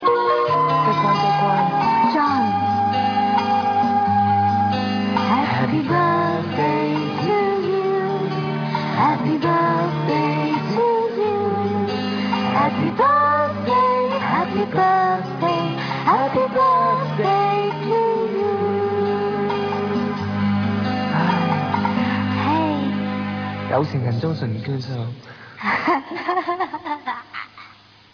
LIVE
9. Ekin and Vivian singing "Happy Birthday"